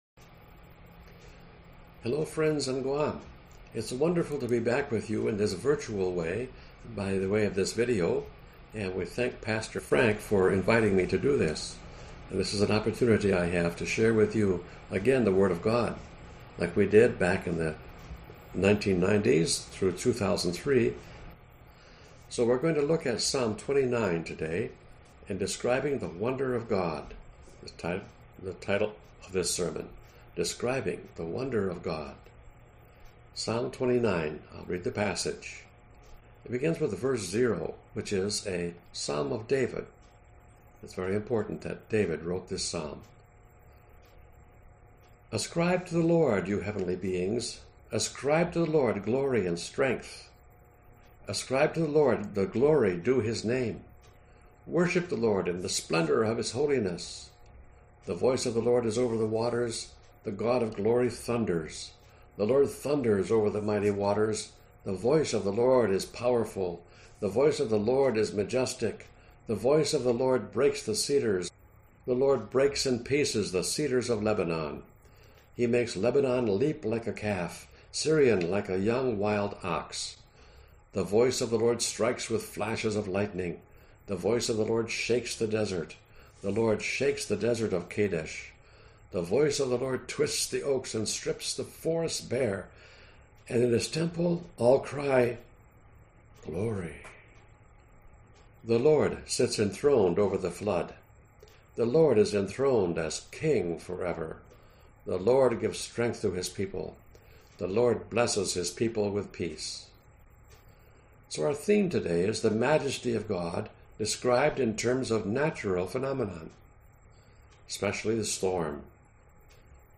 (presented to the church through video)